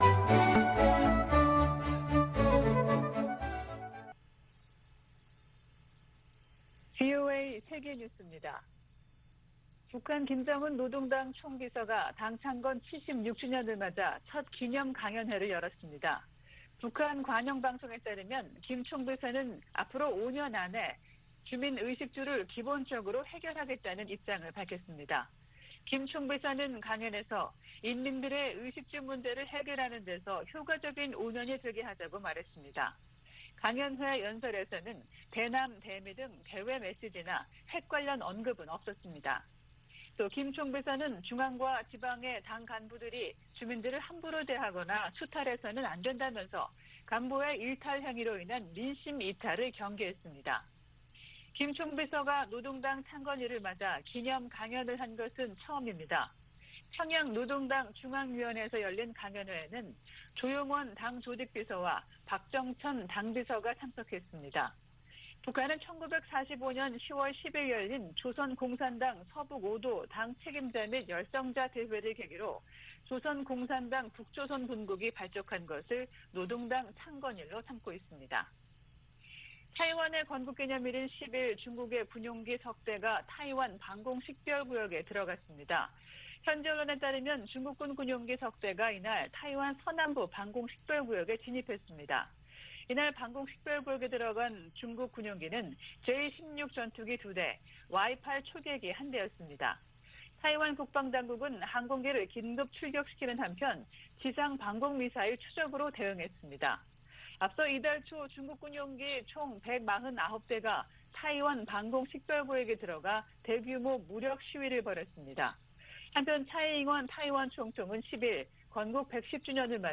VOA 한국어 아침 뉴스 프로그램 '워싱턴 뉴스 광장' 2021년 10월 12일 방송입니다. 북한이 남북 통신연락선을 복원한 지 일주일이 지났지만 대화에 나설 조짐은 보이지 않고 있습니다. 미국은 지난해 정찰위성 2개를 새로 운용해 대북 정보수집에 활용하고 있다고 미 국가정찰국이 밝혔습니다. 세계 300여 개 민간단체를 대표하는 40개 기구가 10일 북한 노동당 창건 76주년을 맞아 유엔 회원국들에 공개서한을 보냈습니다.